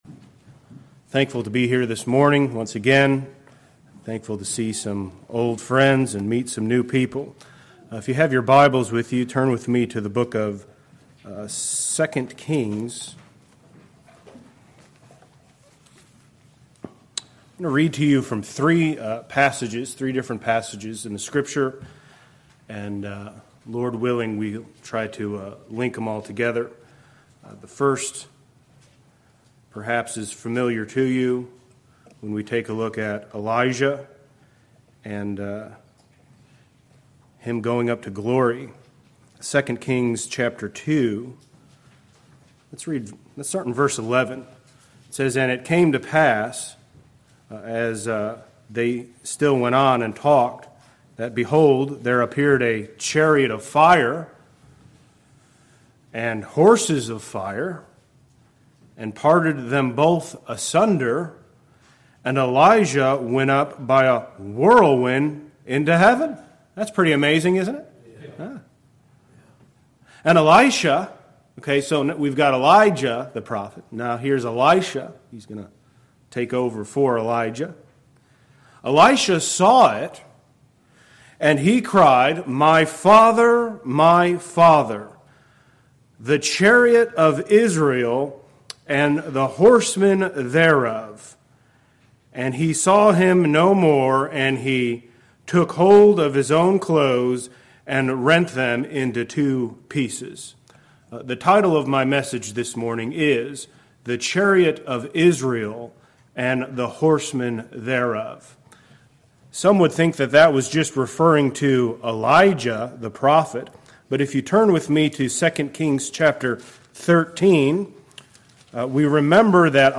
Saturday Morning of 2025 Annual Meeting